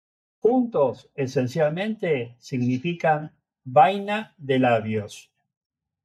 Pronounced as (IPA) /ˈlabjos/